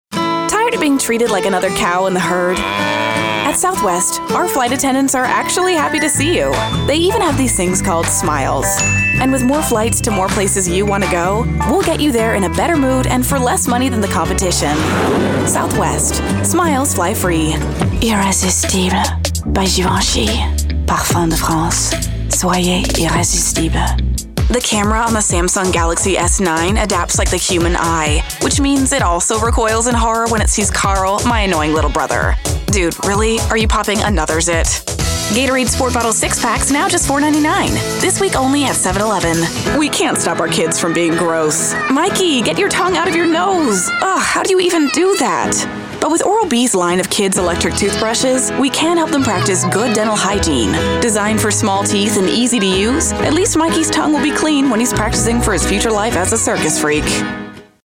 Bande-démo voix off
10 - 40 ans - Contralto Mezzo-soprano